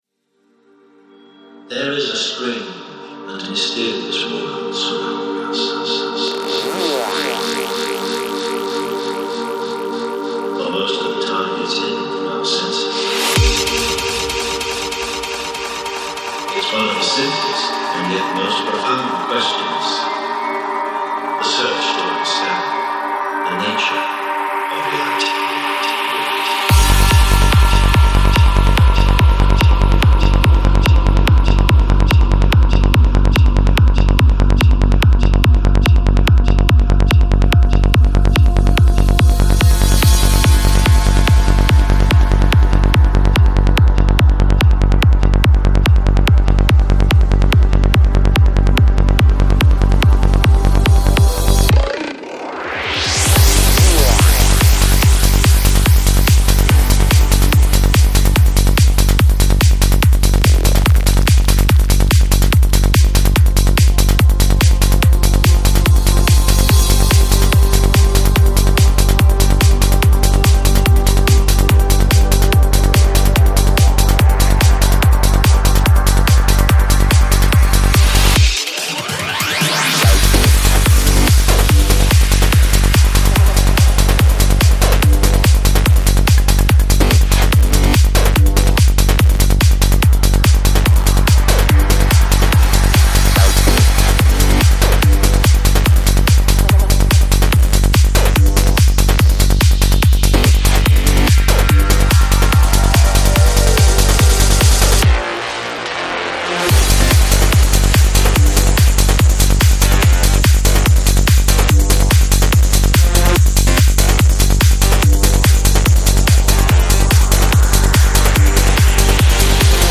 Жанр: Trance
Psy-Trance